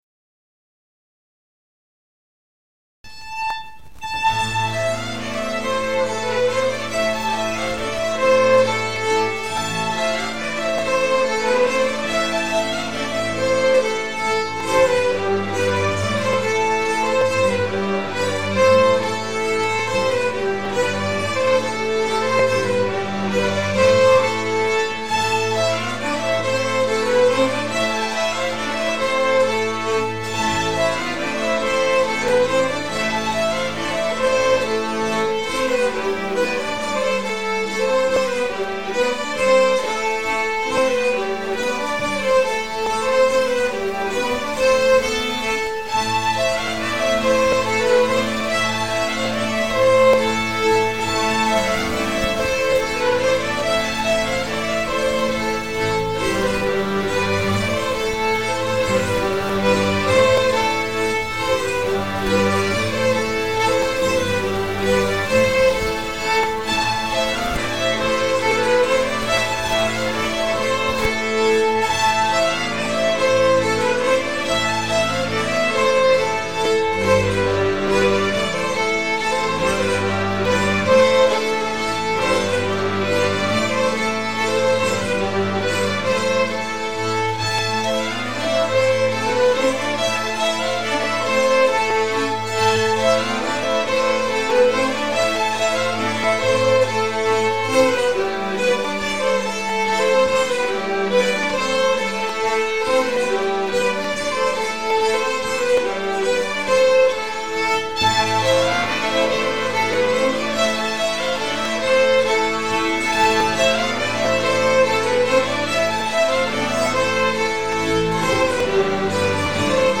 Allspelslåtar Slottsskogsstämman 2025